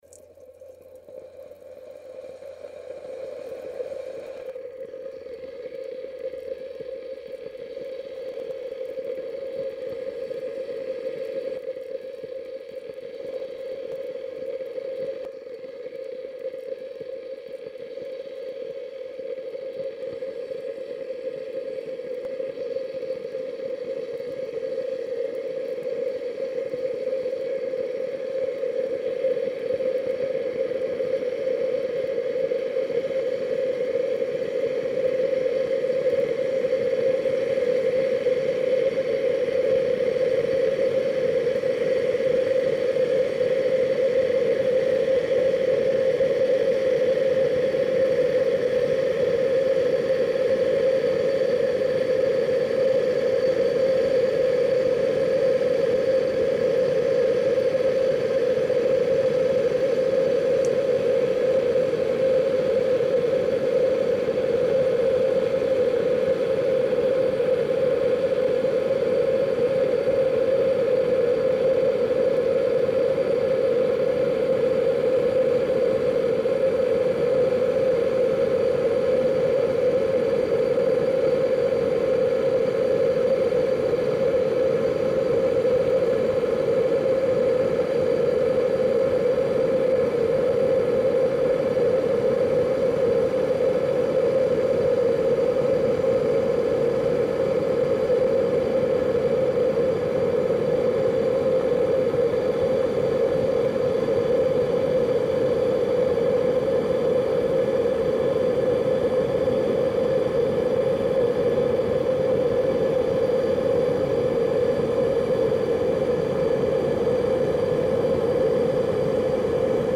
Звуки электрического чайника
На этой странице собраны звуки электрического чайника: от включения до характерного щелчка при завершении кипячения.
Нагрев воды и процесс кипения